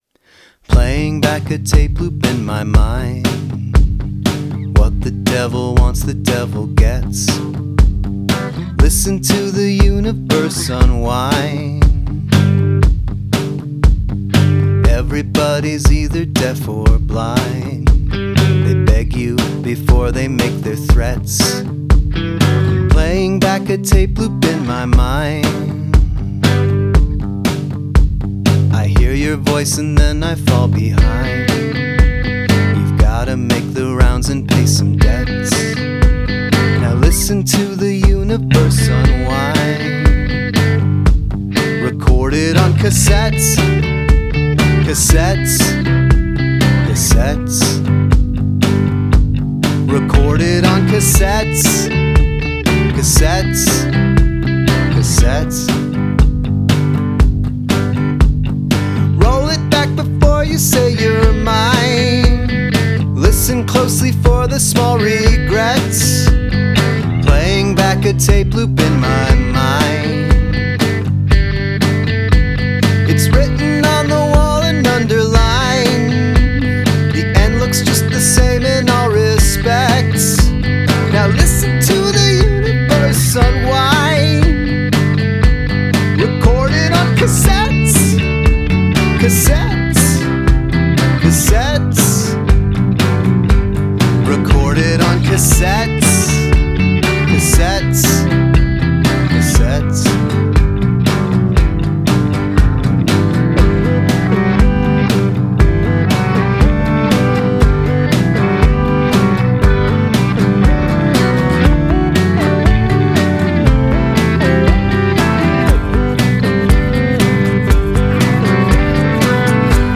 It had a real roundabouty cassettey sort of feel.